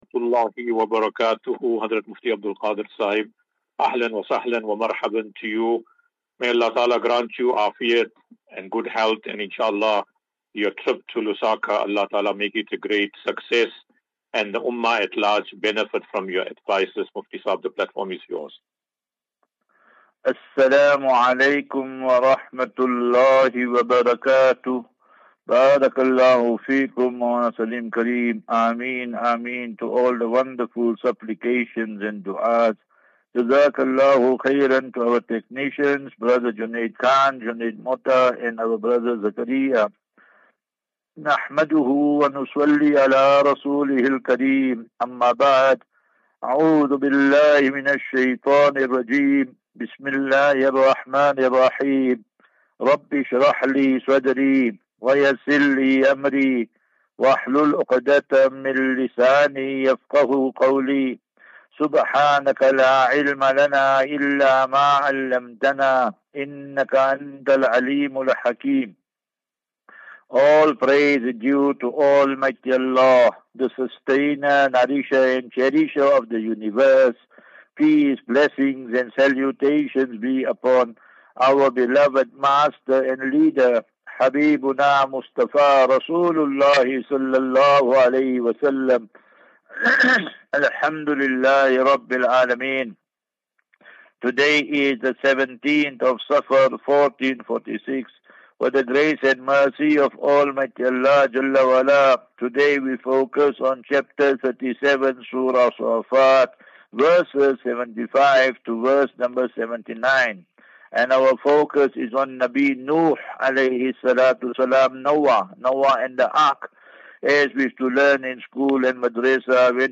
Assafinatu Illal - Jannah. QnA